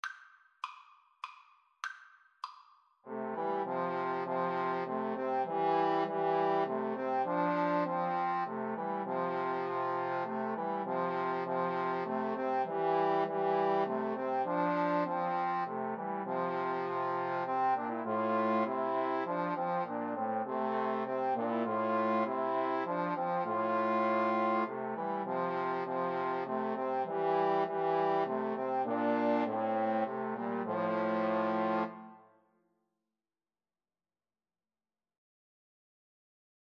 Christian Christian Trombone Trio Sheet Music Come, Thou Fount of Every Blessing
Trombone 1Trombone 2Trombone 3
F major (Sounding Pitch) (View more F major Music for Trombone Trio )
3/4 (View more 3/4 Music)
Trombone Trio  (View more Easy Trombone Trio Music)
Classical (View more Classical Trombone Trio Music)